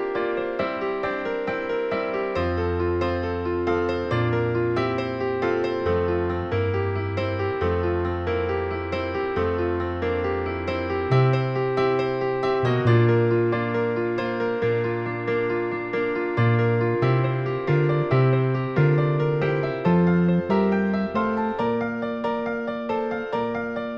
No Harmonica Rock 4:34 Buy £1.50